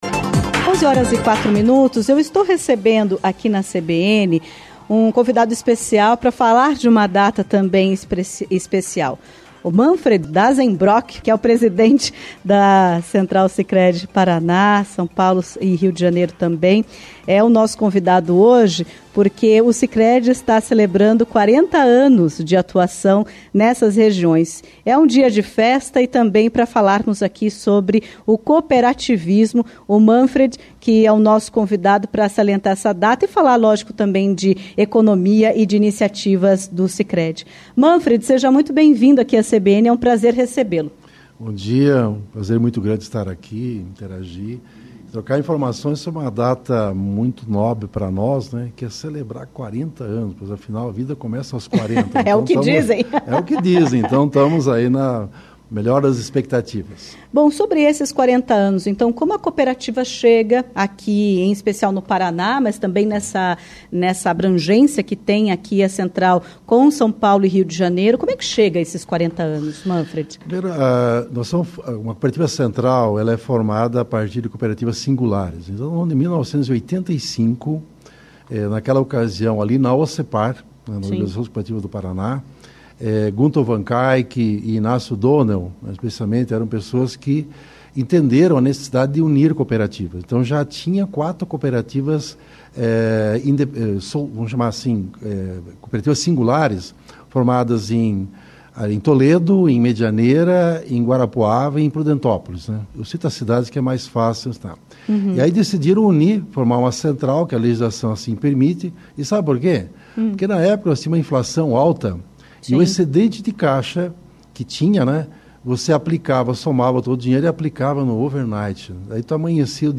Central Sicredi PR/SP/RJ completa 40 anos neste mês de março, celebrando uma marca de destaque no cooperativismo de crédito. Em entrevista à CBN Curitiba nesta terça-feira (18)